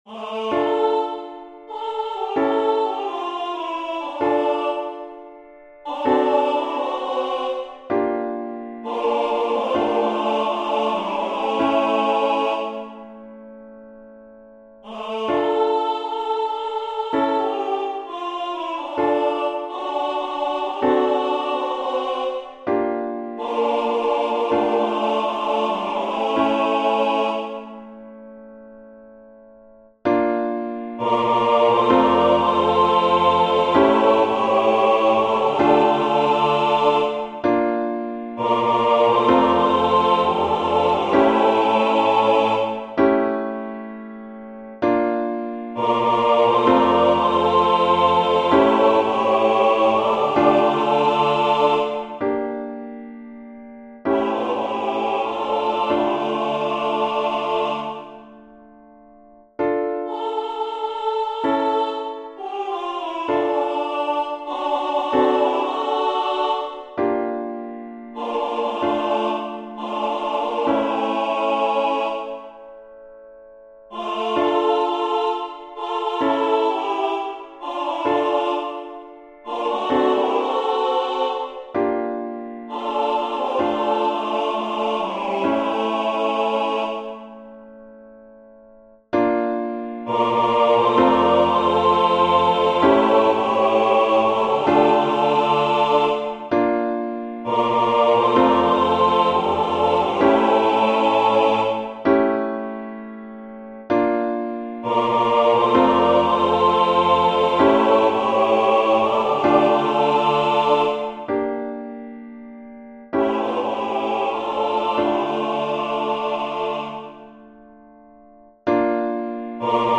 CHÓR